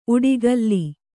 ♪ uḍigalli